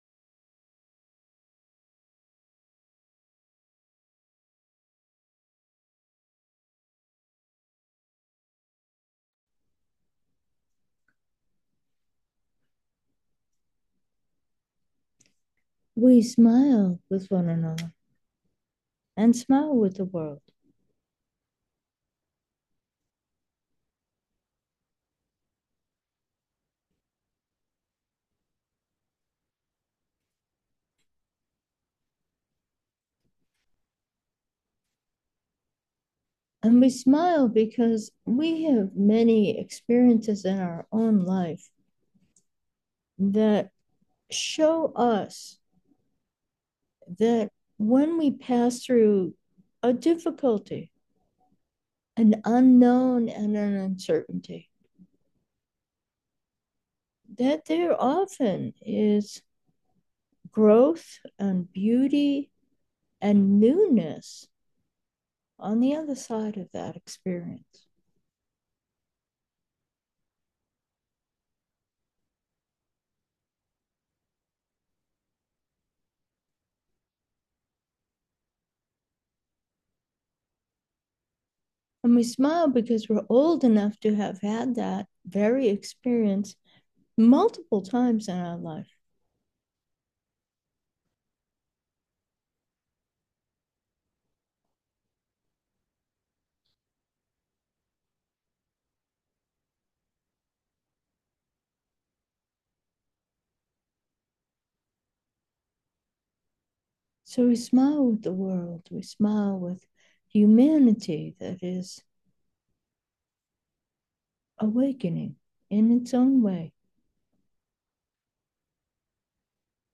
Meditation: breath 10, evidence of wholeness